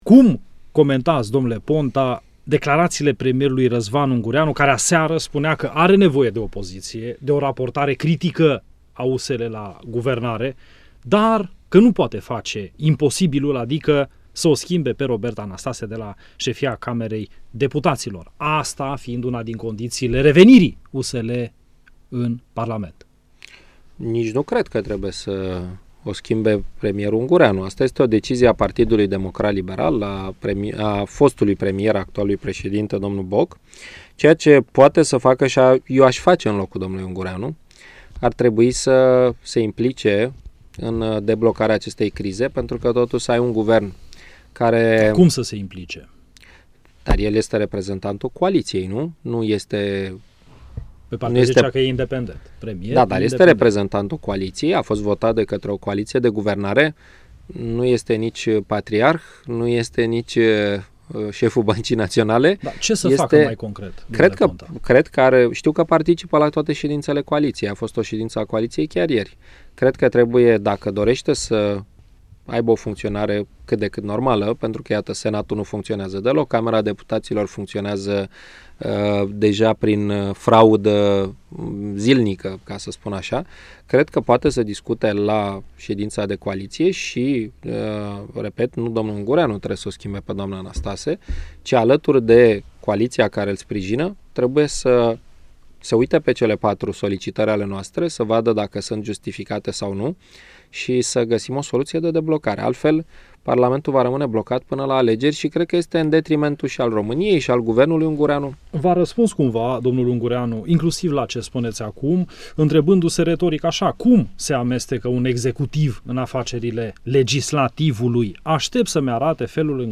Asculta interviul integral cu Victor Ponta (20 min)